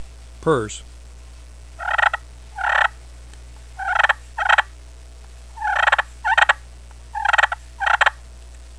Listen to 9 seconds of purrs
• Makes excellent raspy and smooth yelps, clucks, purrs, whines, and cutts at any volume
ccscratchpurrs9.wav